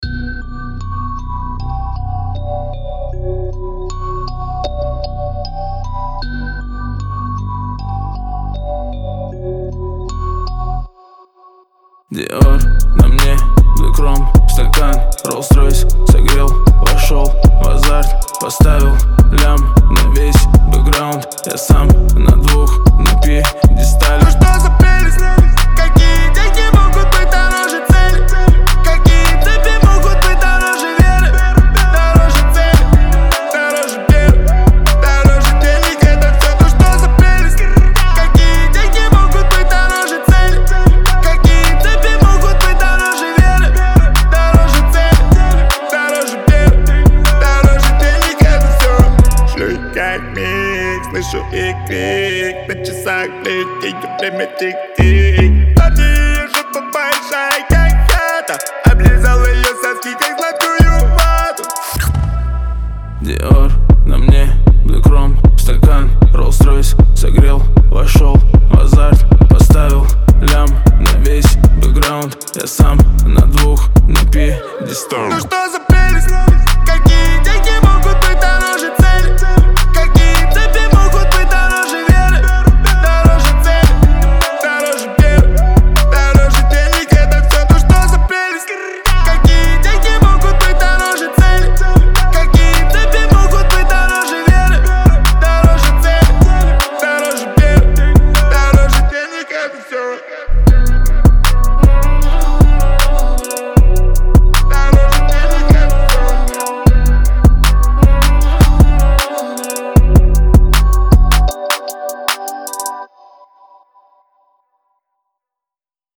выполненный в жанре хип-хоп.